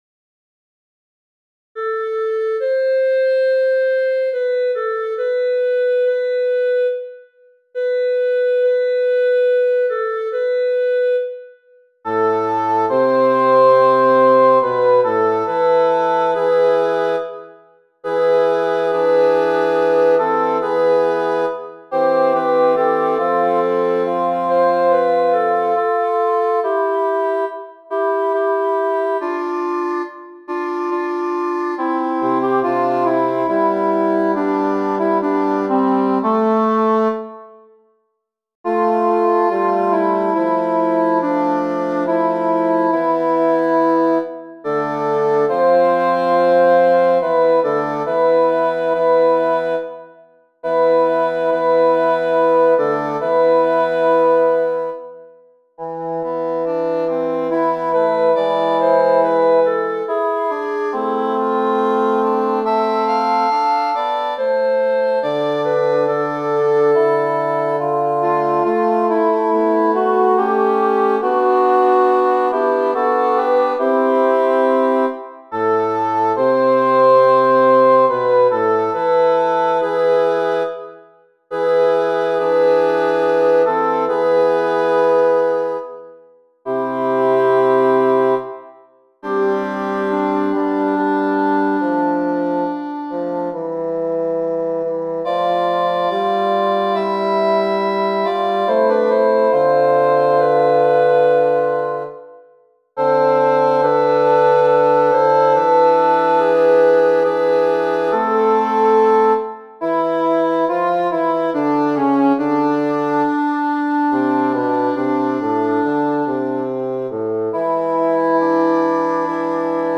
Voicing/Instrumentation: SATB
Choir with Congregation together in certain spots
His music blends early music, 20th-century elements, and fundamentalist musical traditions